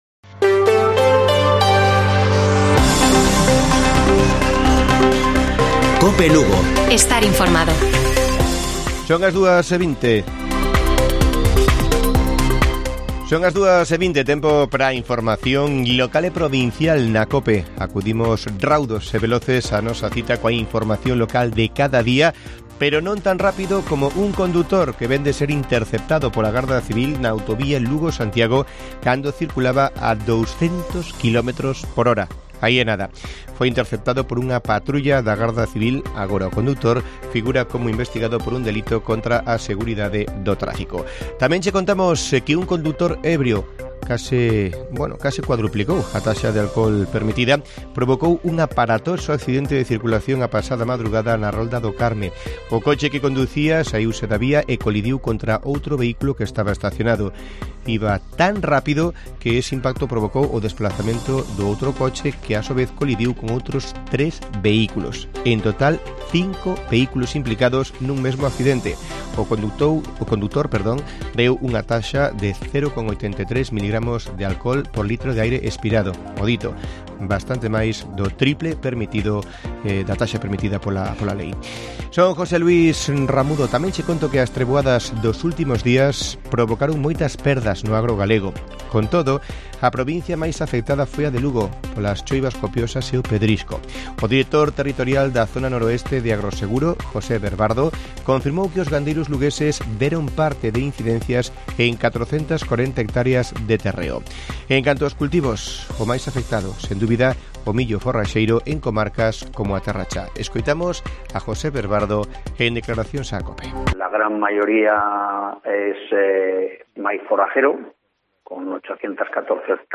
Informativo Mediodía de Cope Lugo. 14 DE JUNIO. 14:20 horas